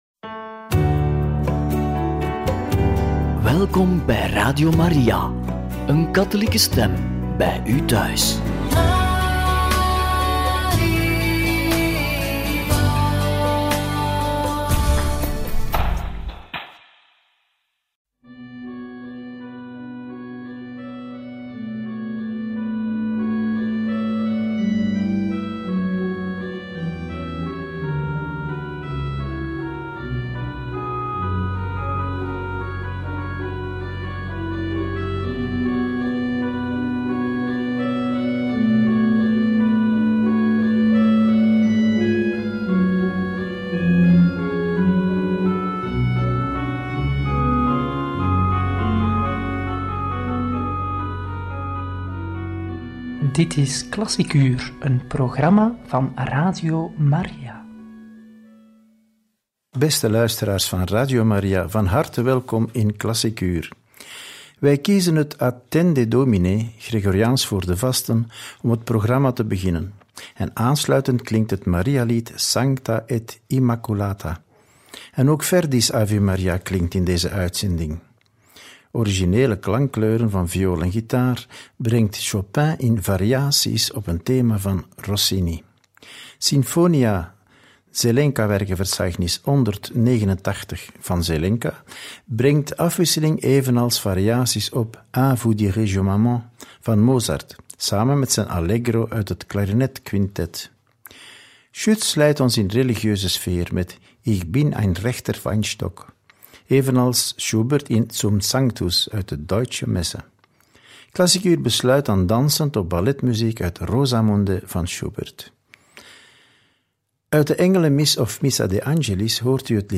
Gregoriaans, Marialiederen, viool, gitaar en balletmuziek – Radio Maria